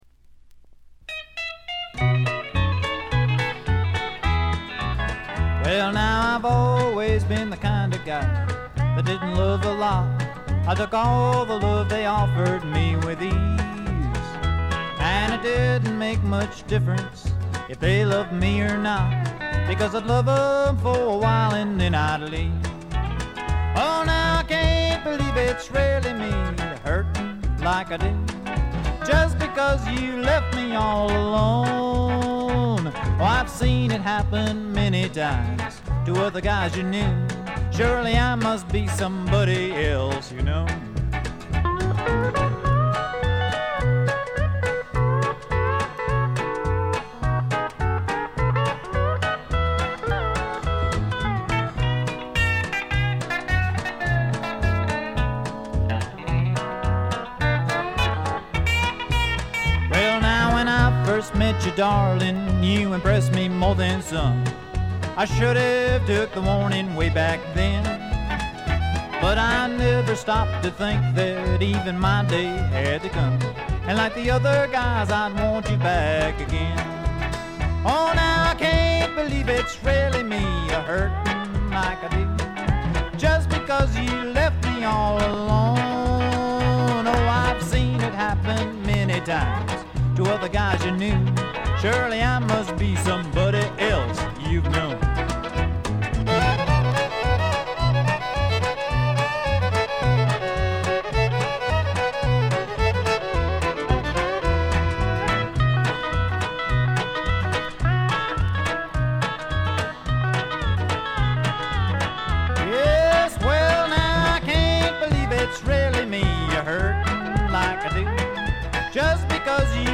B2の突起で大きめのプツ音を4回発します。
試聴曲は現品からの取り込み音源です。